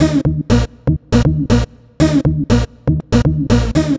TSNRG2 Lead 022.wav